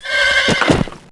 Heroes3_-_Unicorn_-_DeathSound.ogg